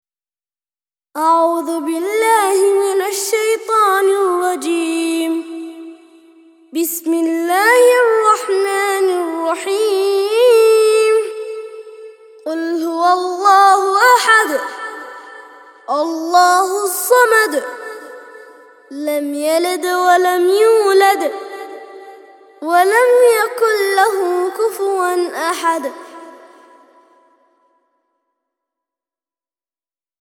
112- سورة الاخلاص - ترتيل سورة الاخلاص للأطفال لحفظ الملف في مجلد خاص اضغط بالزر الأيمن هنا ثم اختر (حفظ الهدف باسم - Save Target As) واختر المكان المناسب